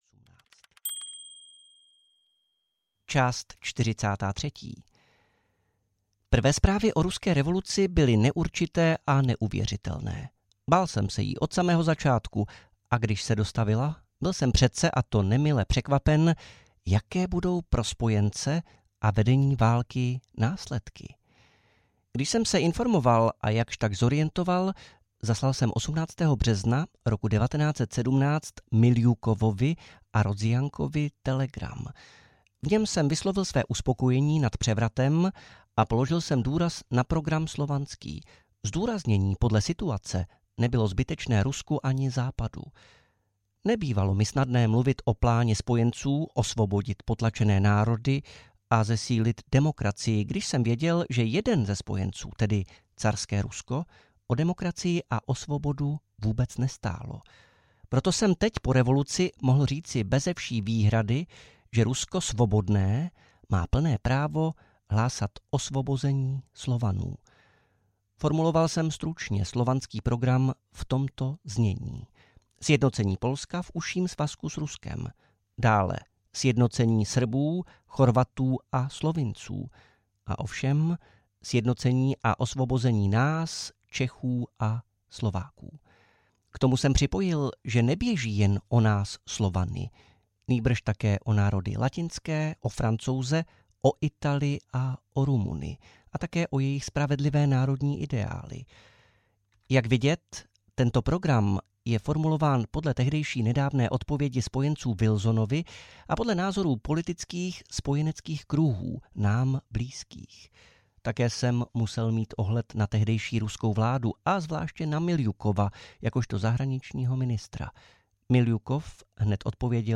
Světová revoluce – část 5 audiokniha
Ukázka z knihy